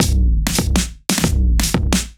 Index of /musicradar/off-the-grid-samples/110bpm
OTG_Kit 1_HeavySwing_110-D.wav